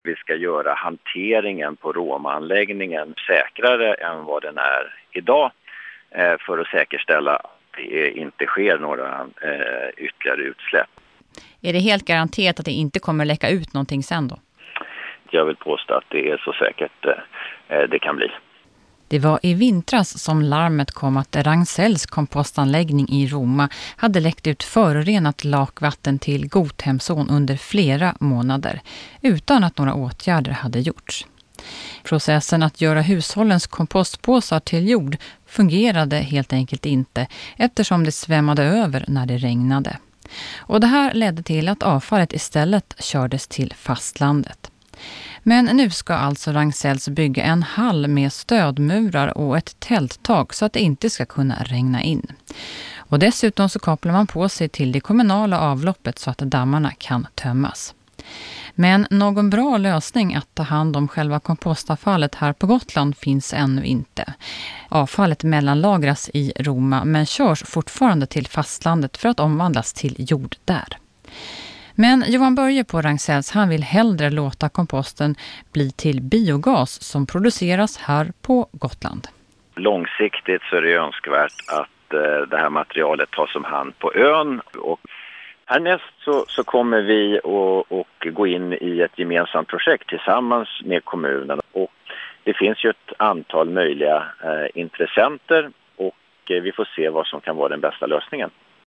Jämför med vad samme person sade vid förra intervjun: